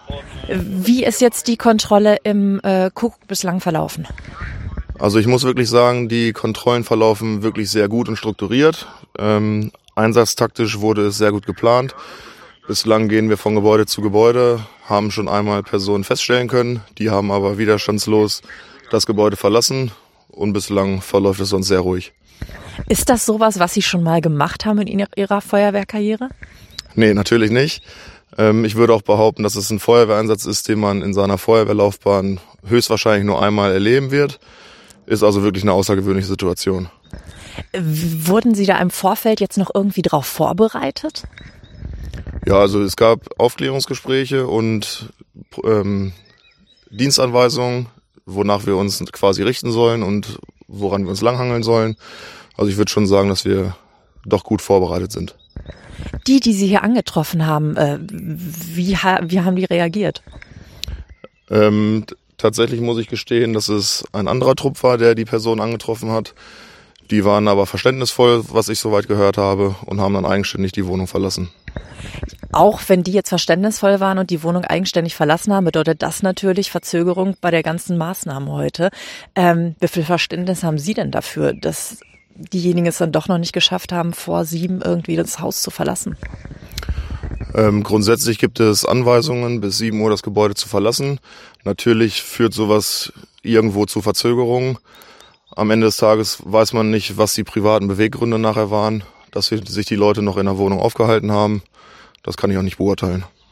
aus dem Hamelner Ortsteil Rohrsen am Kuckuck: